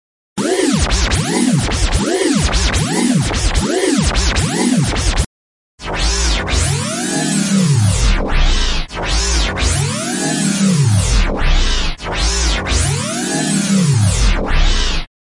这很奇怪 " 口齿不清的僵尸
描述：合成噪音，如挡风玻璃刮水器，或“划伤”乙烯基，或胶带绕线。
Tag: swazzle pareidolia 大脑 卡通 处理 循环 擦拭 电子 合成 怪异 搔抓 雨刮器 乙烯基 磁带 毛刺 挡风玻璃 后台 划伤 纪录 实验 噪音